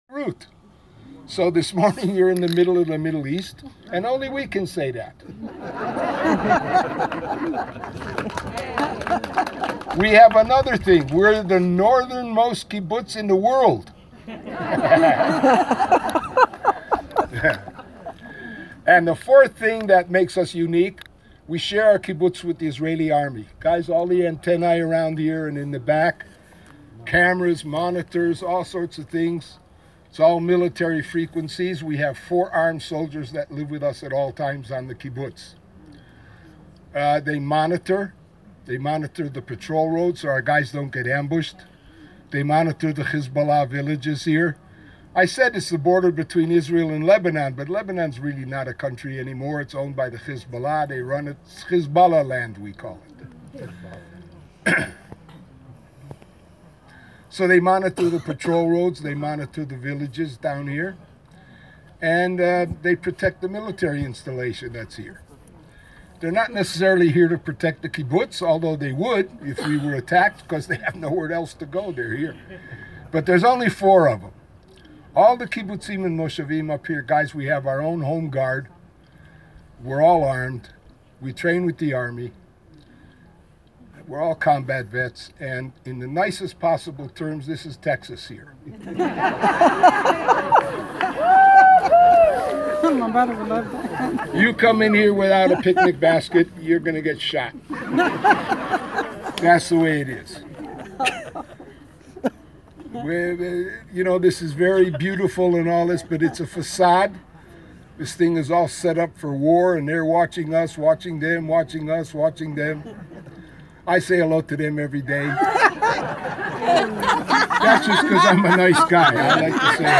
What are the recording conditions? Lebanon border- We began our day at Misgav Am, a kibbutz at the northern most point in Israel.